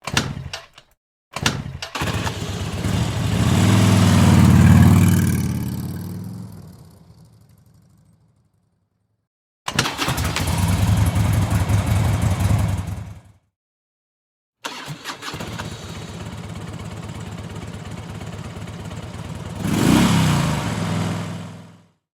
Multiple Motorcycle Starts
Multiple Motorcycle Starts is a free sfx sound effect available for download in MP3 format.
yt_Zk8FOWB5I8k_multiple_motorcycle_starts.mp3